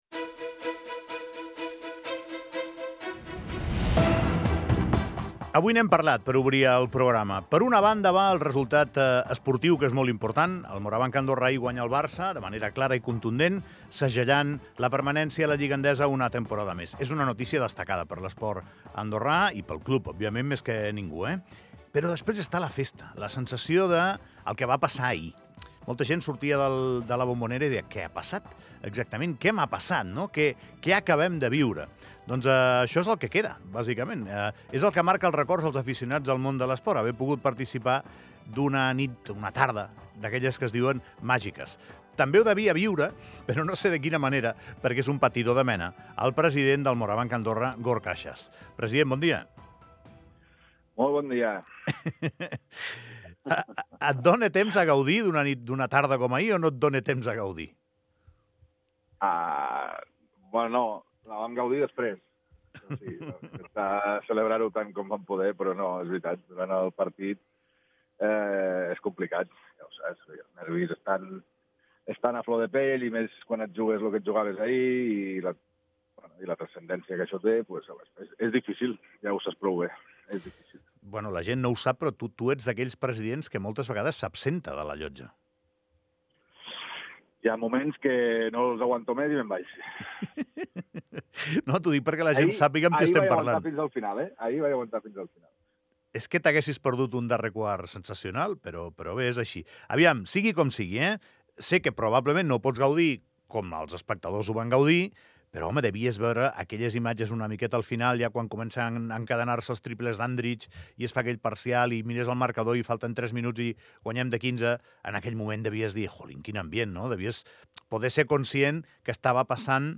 Trucada d'actualitat